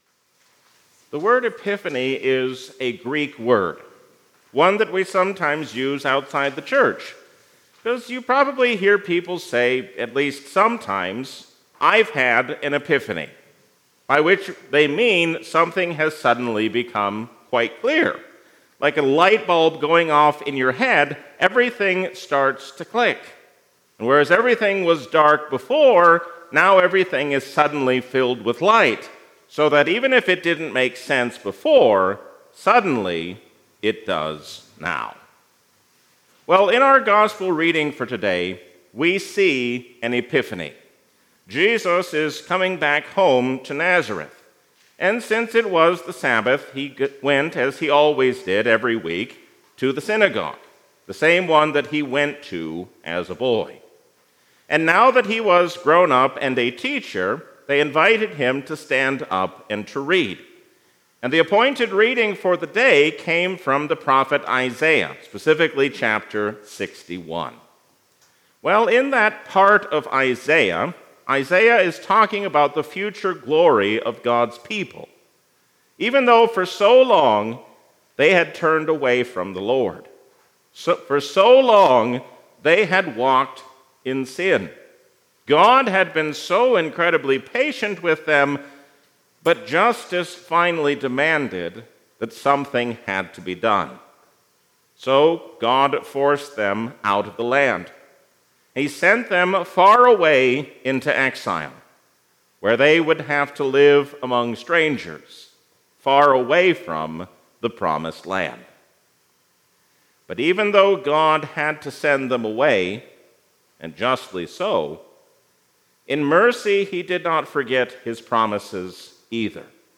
A sermon from the season "Epiphany 2026." Always be on guard against error hiding itself behind the truth.